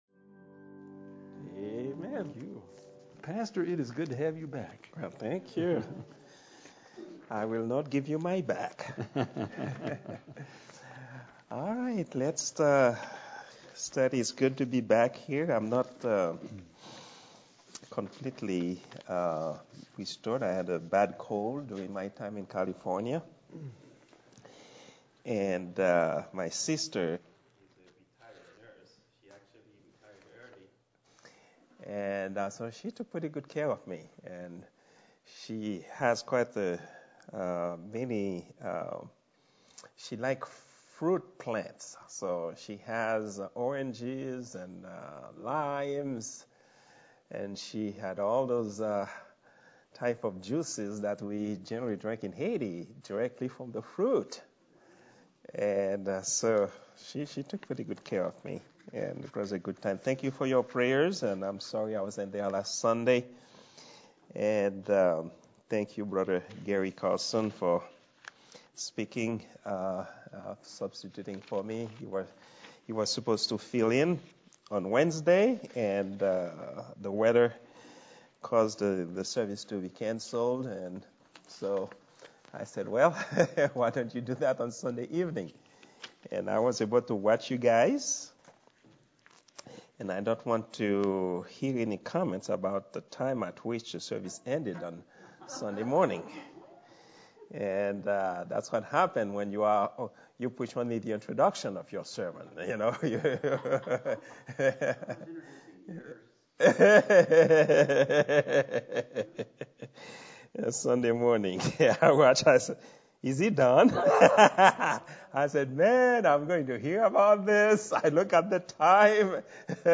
Prayer_Meeting_02_09_2022.mp3